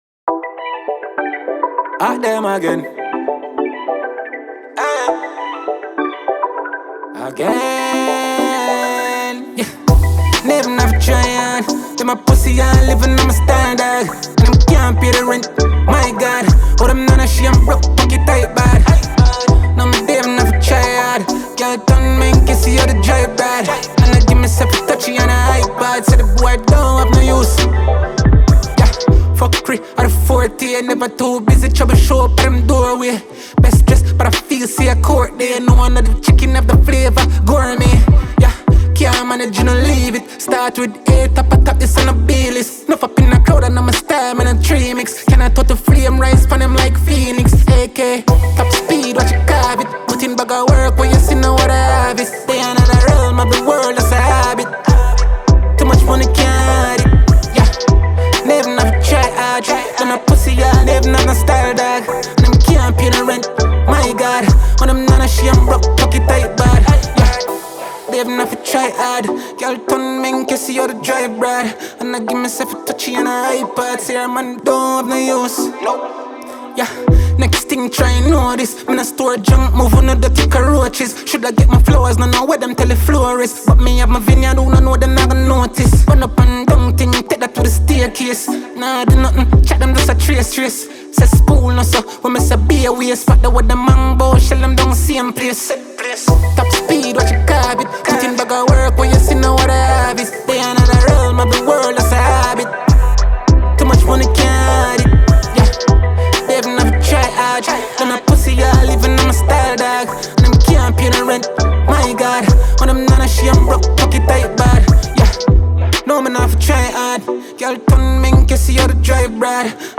Dancehall/HiphopMusic
Jamaican award winning dancehall act